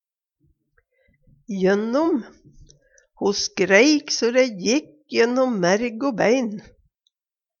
jønnom - Numedalsmål (en-US)